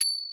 Key-rythm_ching_02.wav